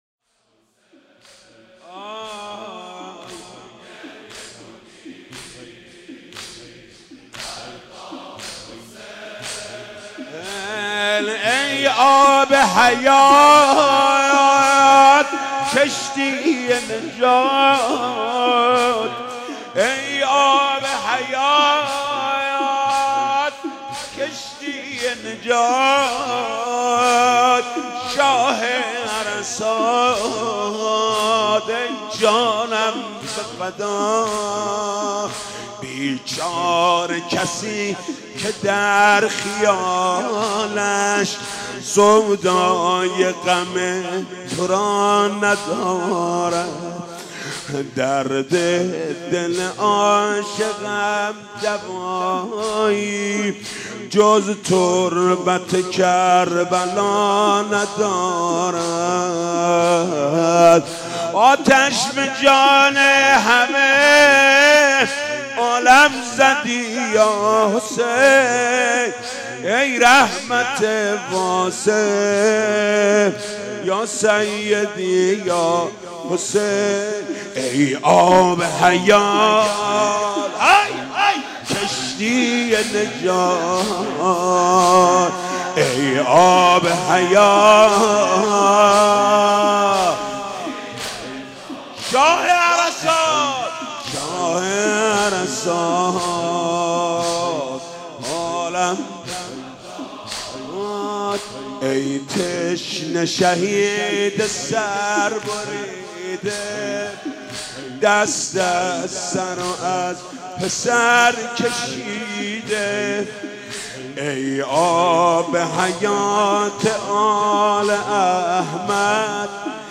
«شهادت امام صادق 1396» زمینه: ای آب حیات کشتی نجات
«شهادت امام صادق 1396» زمینه: ای آب حیات کشتی نجات خطیب: حاج محمود کریمی مدت زمان: 00:06:45